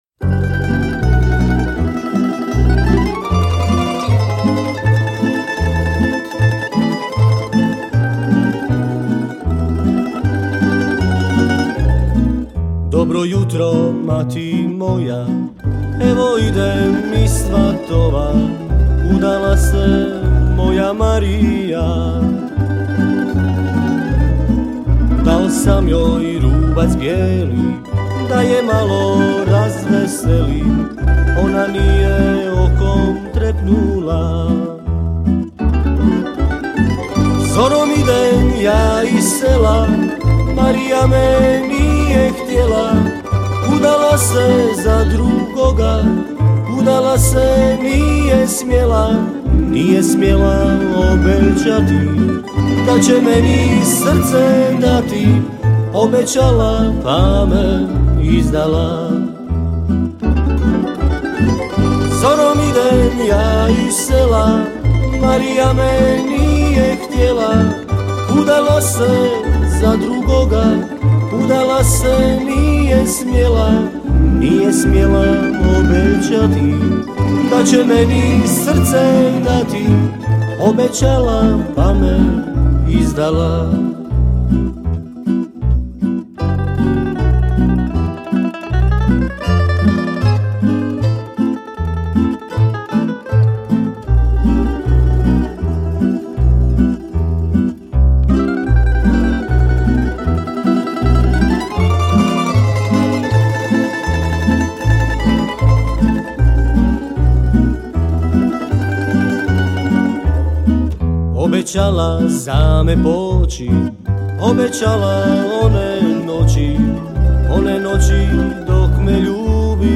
37. Festival pjevača amatera
Zvuci tamburice do kasnih noćnih sati odzvanjali su prepunom dvoranom vatrogasnog doma u Kaptolu.